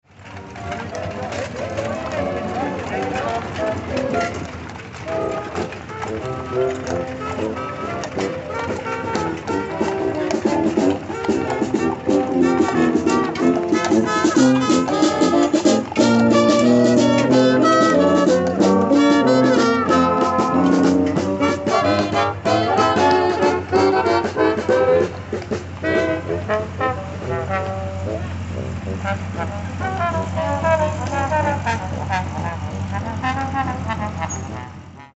The holiday tradition of lighting up Manhattan continued this Friday as the 19th annual Mayor’s Spirit of Holidays Lighted Parade brought hundreds of people out for a little Christmas cheer while still socially distancing.
That is the sound of Fort Riley Band Members as they rode down 11th Street.
Fort-Riley-Band-Members.mp3